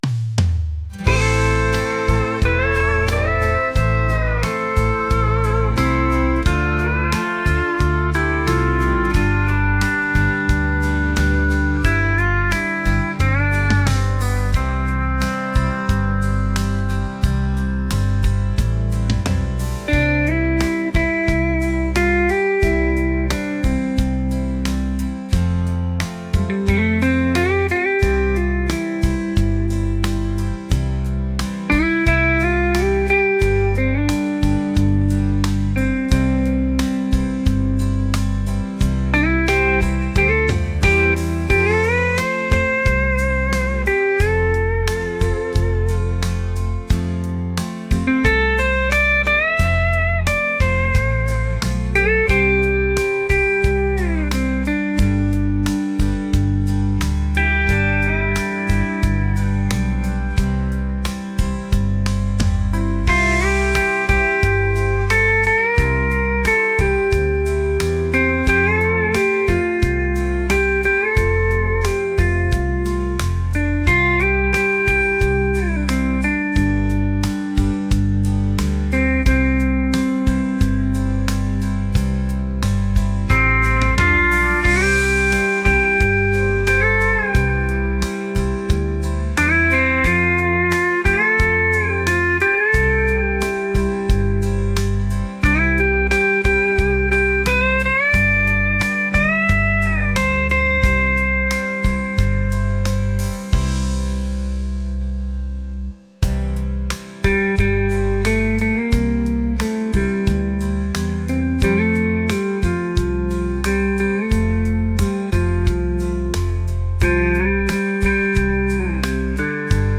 country | soulful